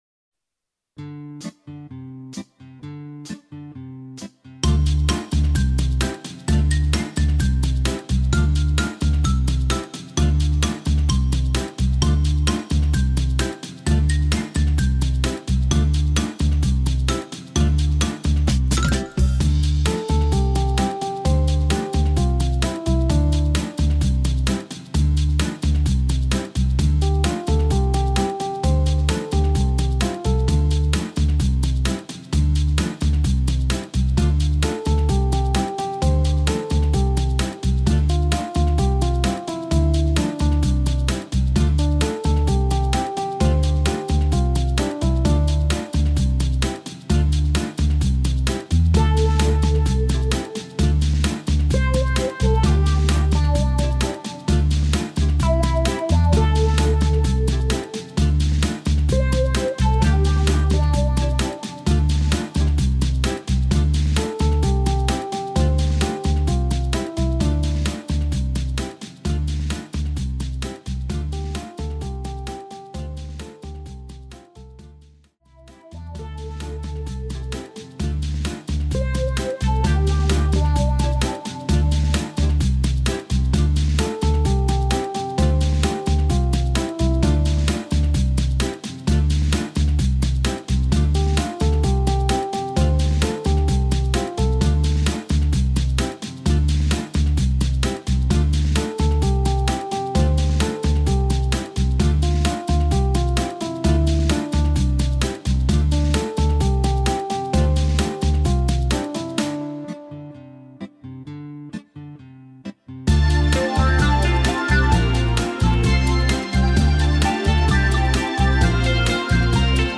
Hoedown/Patter